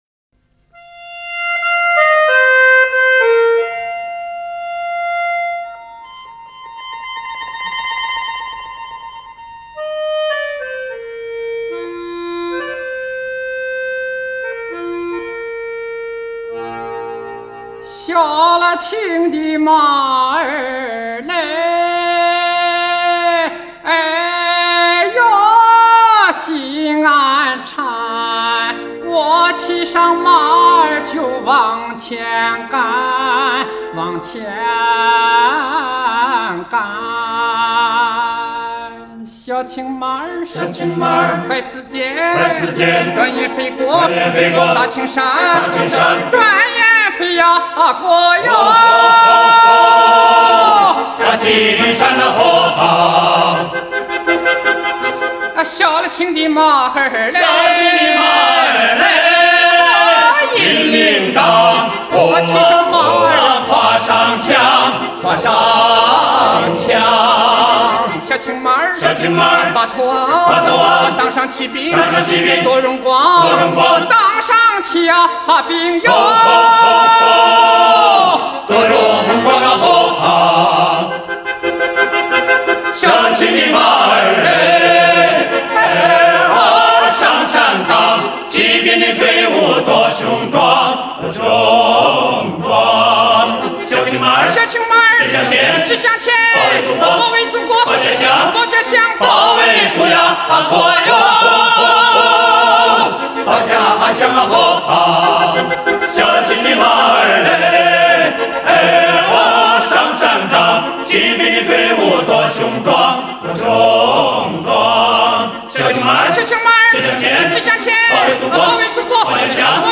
手风琴伴奏 总政文工团歌舞团合唱队演唱